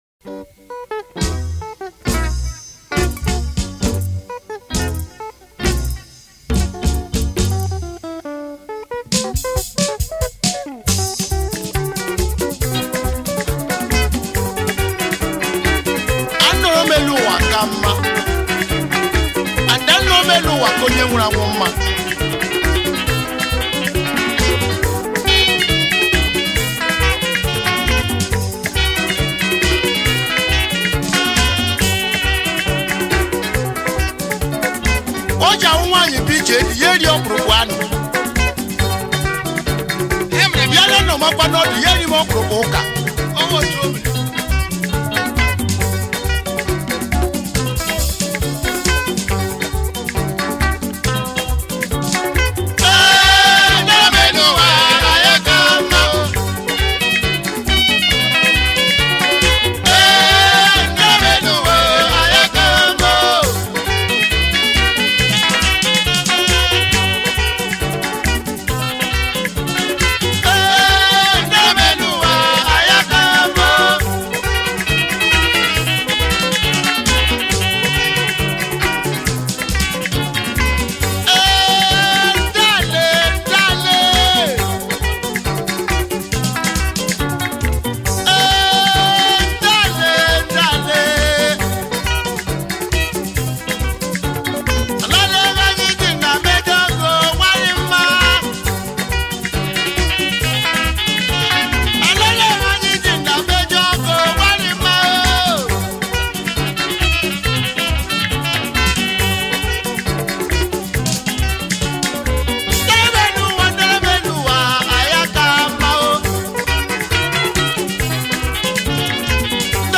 high life